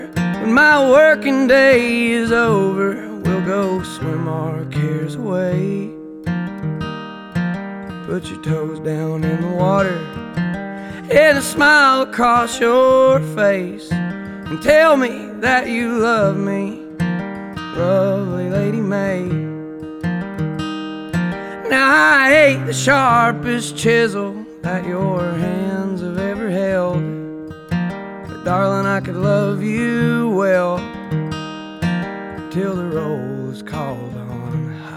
Жанр: Рок / Кантри